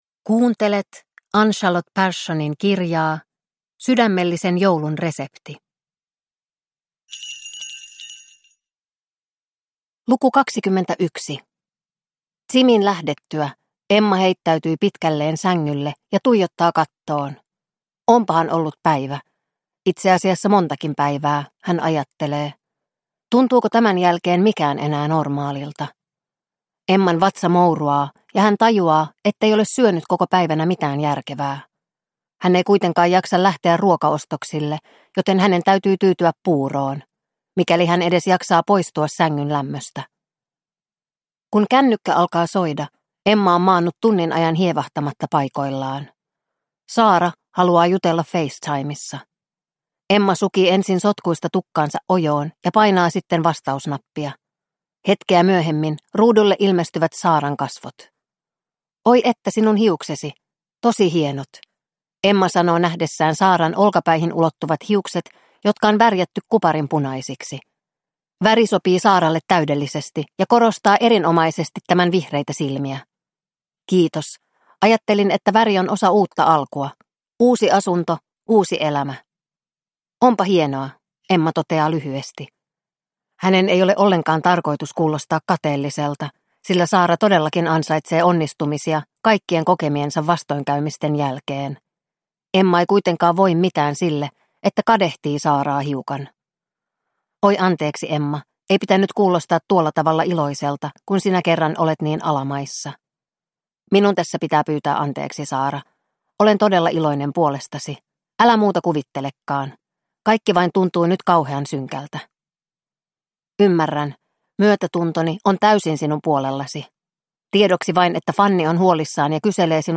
Sydämellisen joulun resepti - Luukku 21 – Ljudbok – Laddas ner